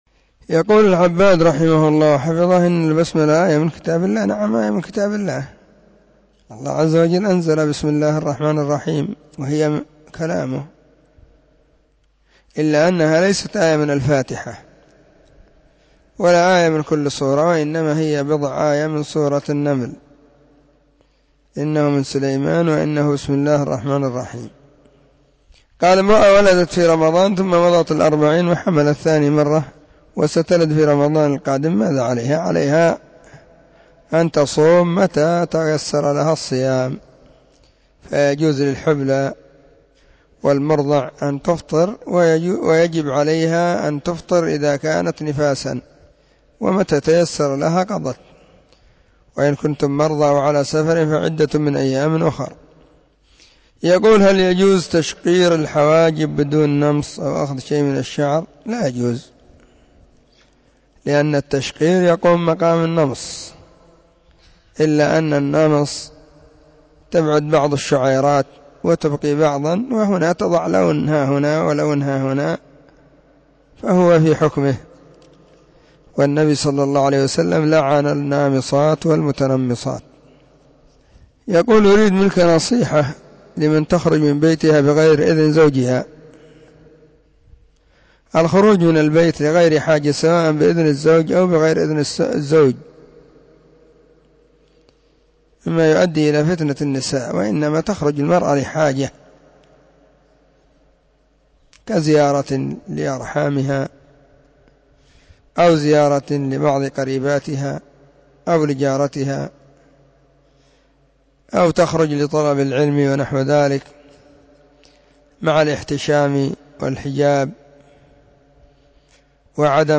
فتاوى ,الثلاثاء 4 /ربيع الثاني/ 1443 هجرية,.